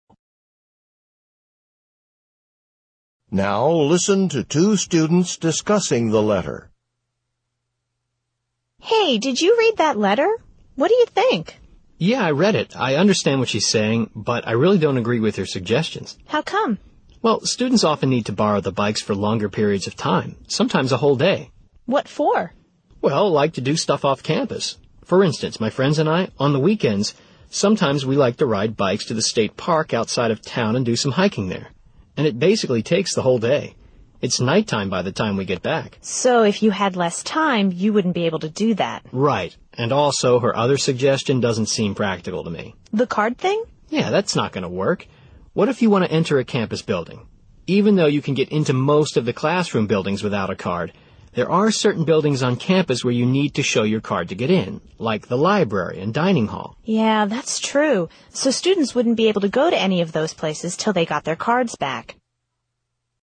The man expresses his opinion about the proposal described in the letter.
tpo33_speaking3_question3_dialog.mp3